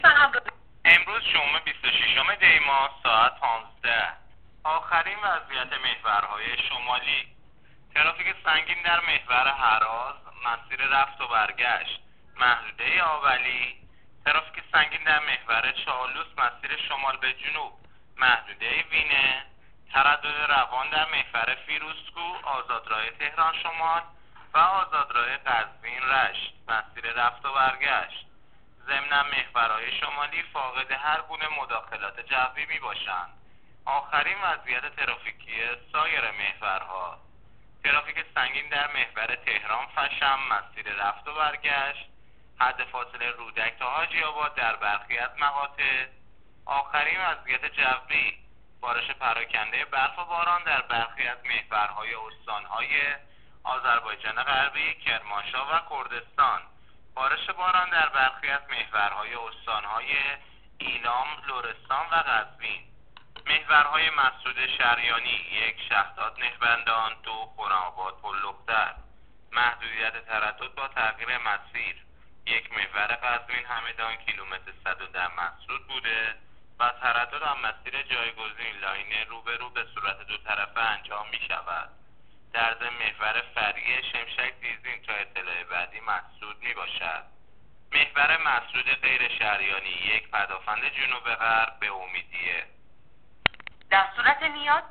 گزارش رادیو اینترنتی از آخرین وضعیت ترافیکی جاده‌ها تا ساعت۱۵ بیست‌وششم دی‌ماه